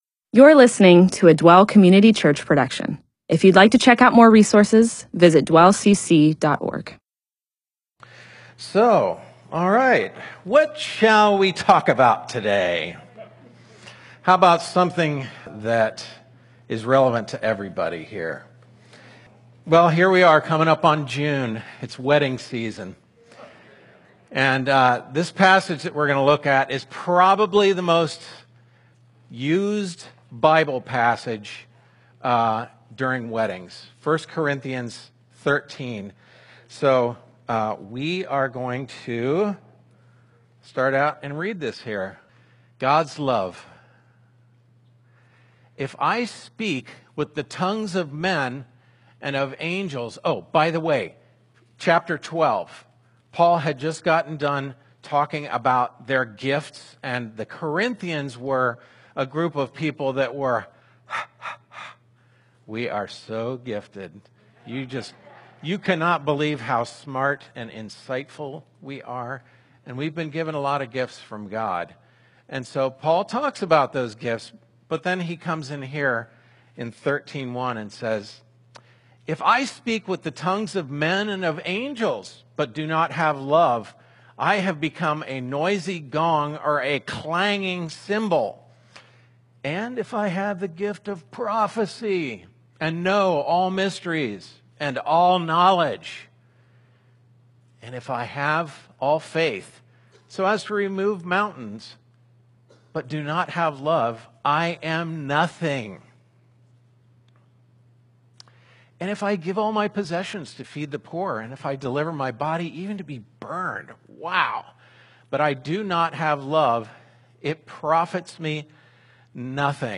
MP4/M4A audio recording of a Bible teaching/sermon/presentation about 1 Corinthians 13:1-8.